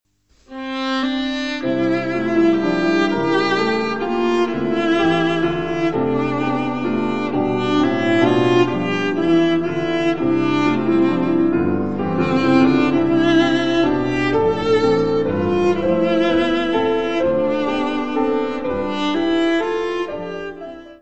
viola
piano
oboé, corne inglês
contrabaixo.
: stereo; 12 cm
Área:  Música Clássica